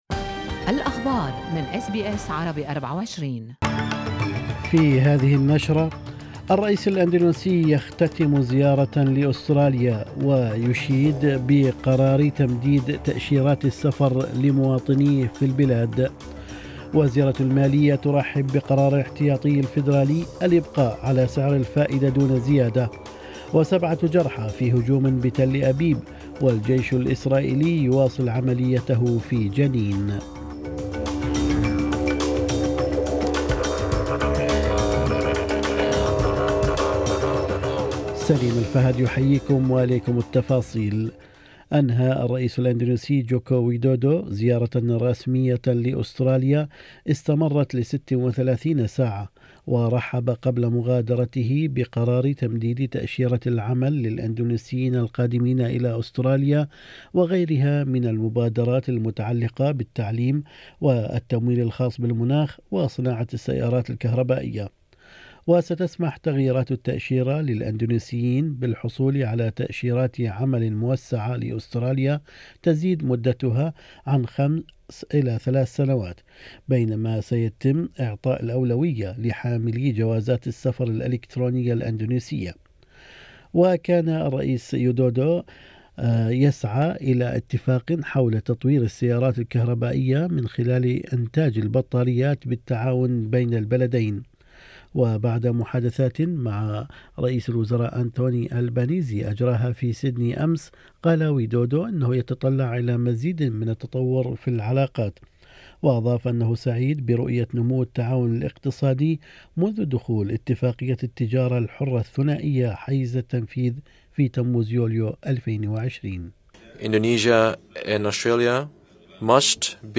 نشرة أخبار الصباح 5/7/2023